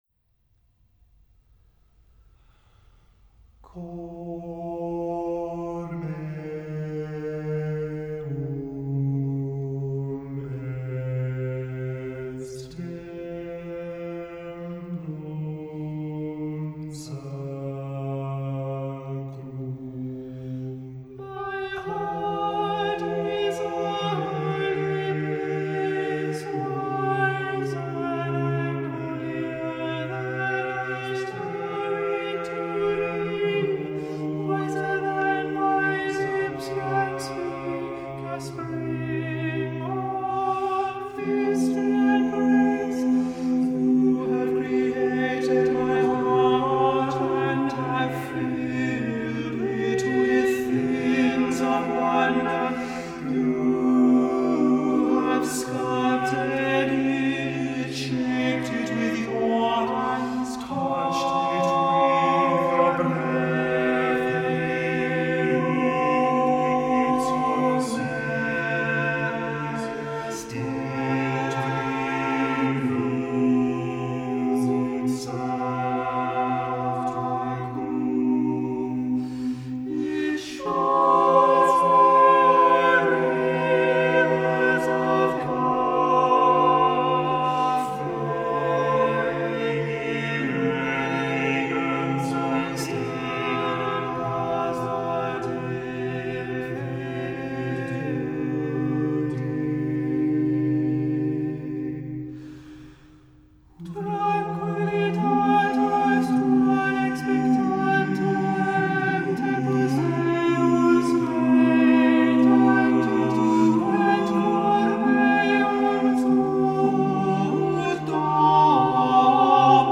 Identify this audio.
Voicing: SATTBB